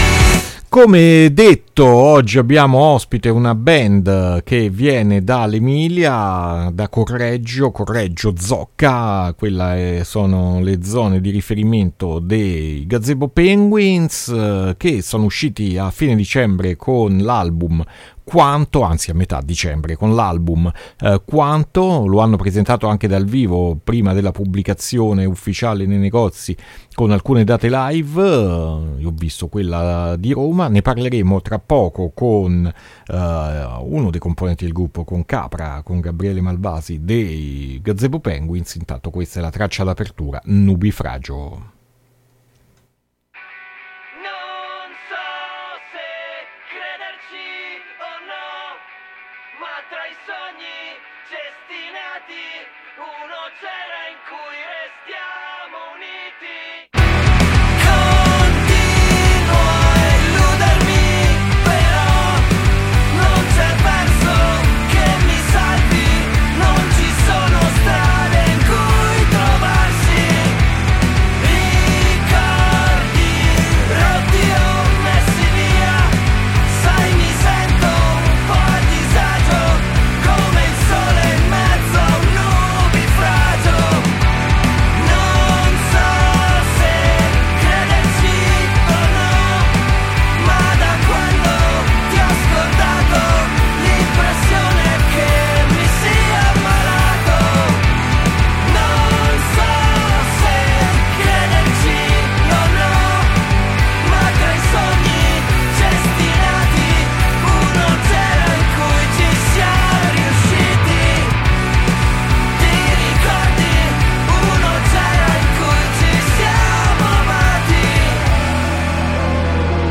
INTERVISTA GAZEBO PENGUINS A PUZZLE 2-1-2023